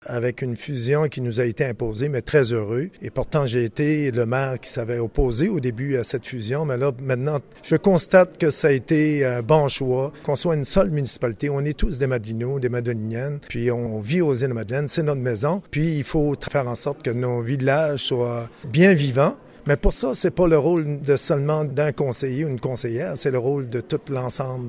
Plus de 300 citoyens se sont rendus à la convocation du conseil municipal à Havre-Aubert, lundi, pour non seulement écouter les élus municipaux sur différents enjeux, mais aussi pour exprimer leurs questionnements.
D’autres ont questionné la reconnaissance de leur conseillère Suzie Leblanc au sein du conseil, ce à quoi l’ensemble des élus, dont Roger Chevarie du village de Fatima, ont répondu que tous les conseiller sont sur le même pied :